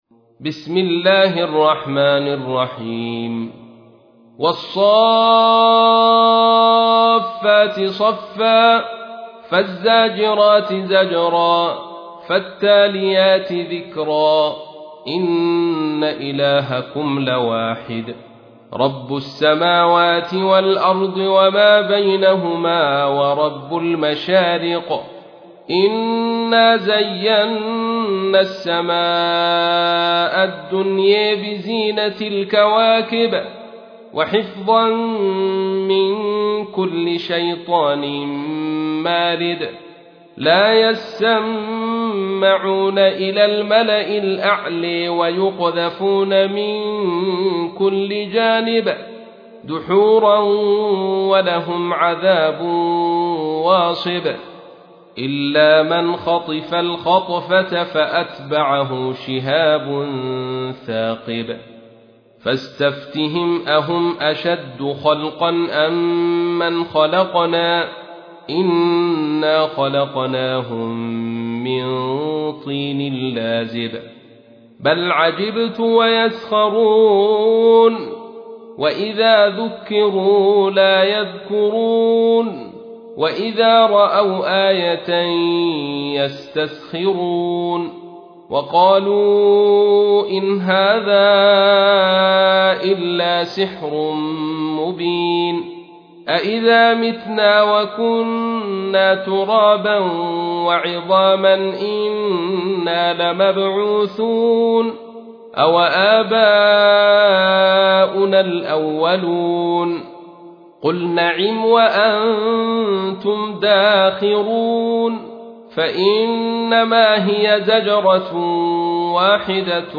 تحميل : 37. سورة الصافات / القارئ عبد الرشيد صوفي / القرآن الكريم / موقع يا حسين